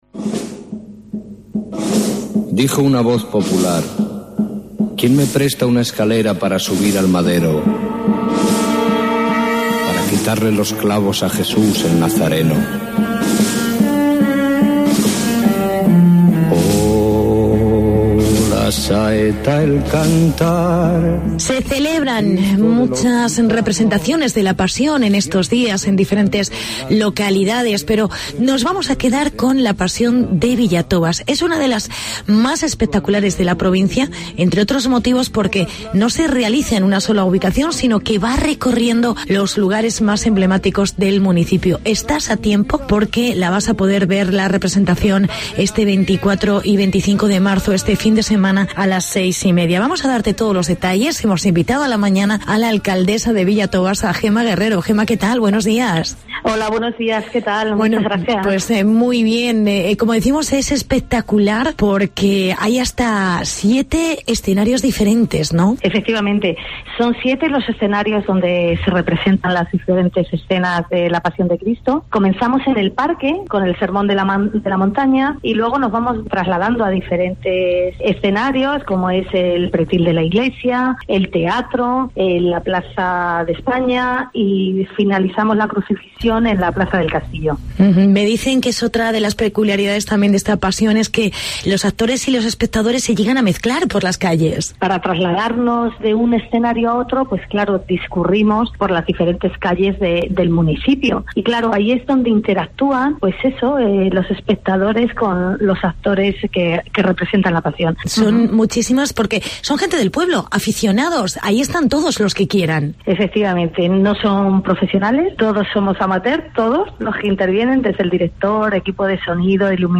entrevista con la alcaldesa: Gema Guerrero